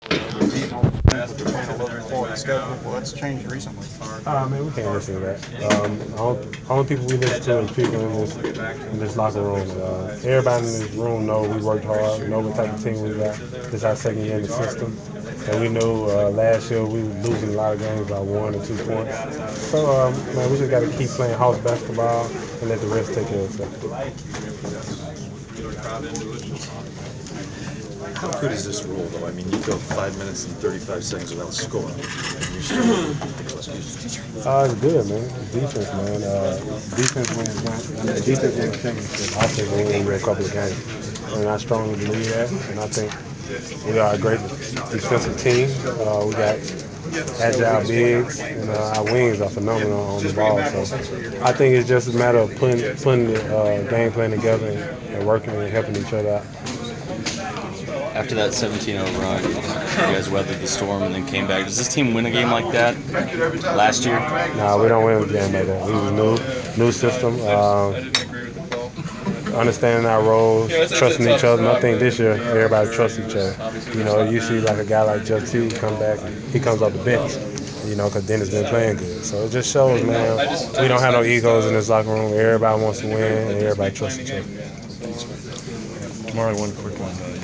Inside the Inquirer: Postgame presser with Atlanta Hawks’ DeMarre Carroll (12/23/14)